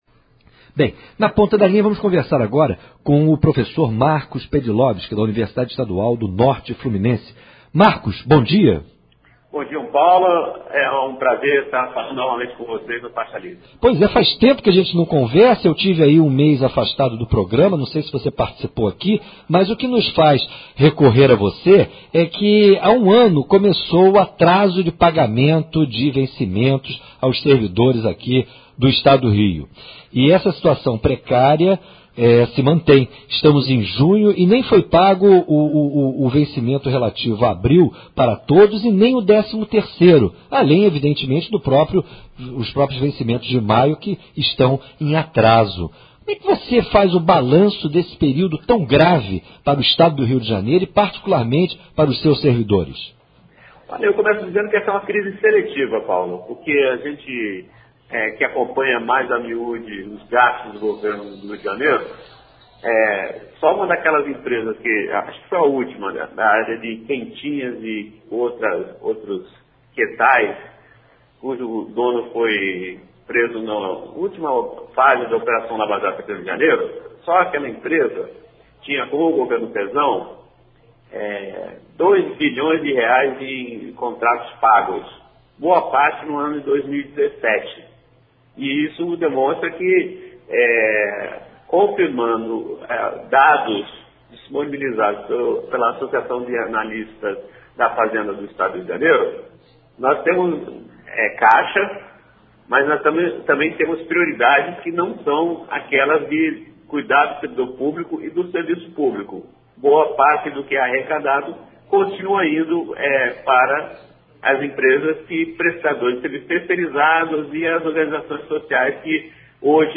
Entrevista no programa “Faixa Livre” sobre o atraso no pagamento dos salários dos servidores